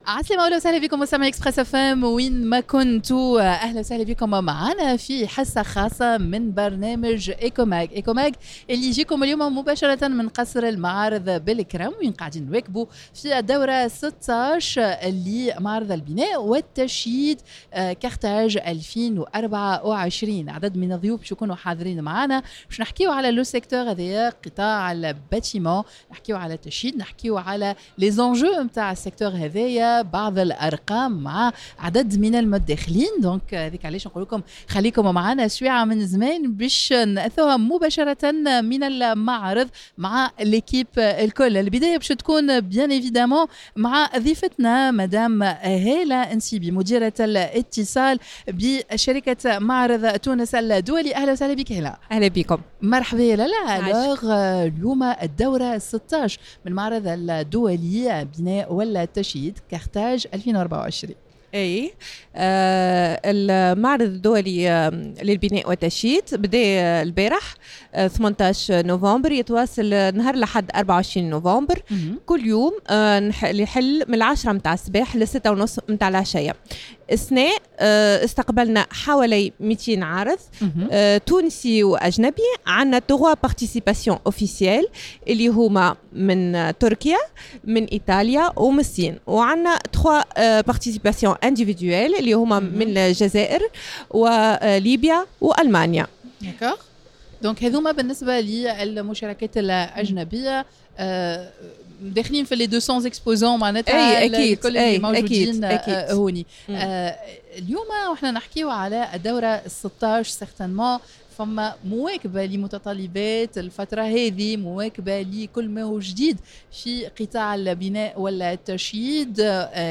en direct dans un plateau spécial du foire El Kram